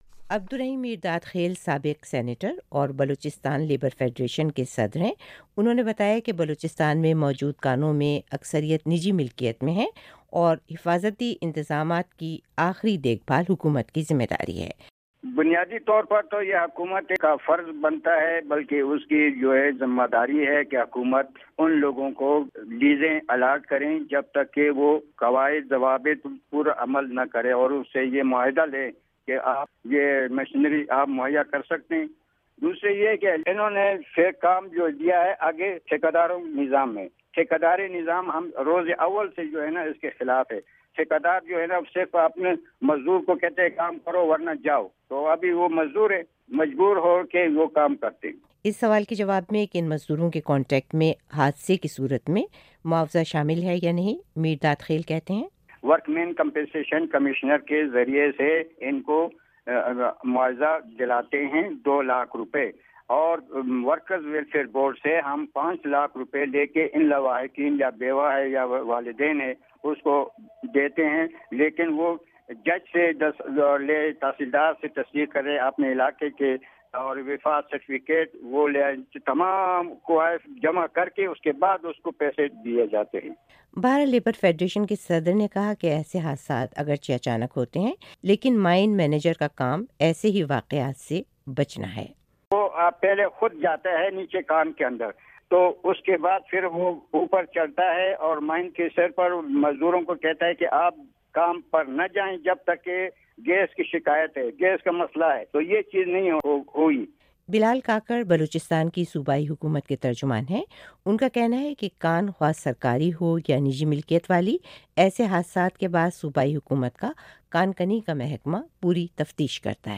بلوچستان میں کانوں کے حادثے کے بارے میں رپورٹ